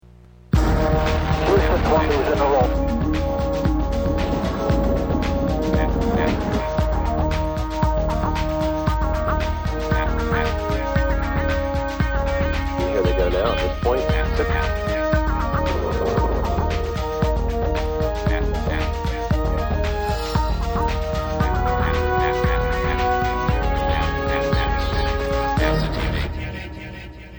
Qso with Norm Thagart on MIR April 1994